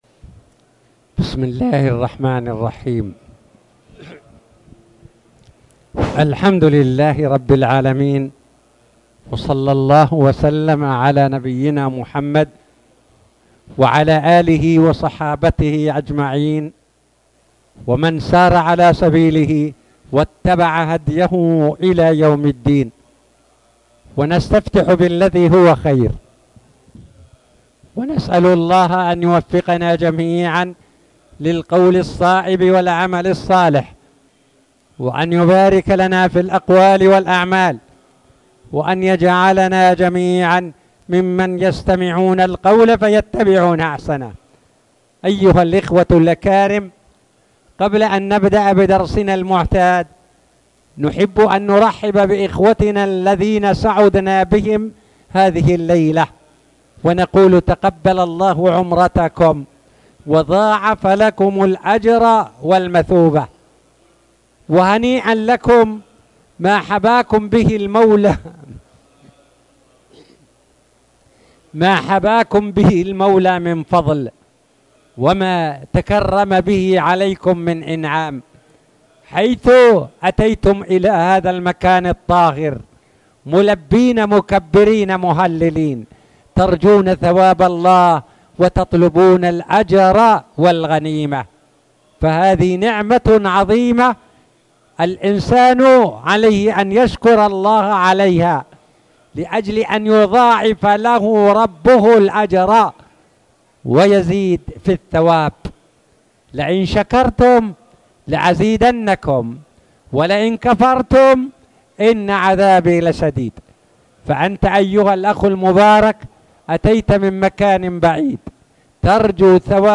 تاريخ النشر ١٢ رجب ١٤٣٨ هـ المكان: المسجد الحرام الشيخ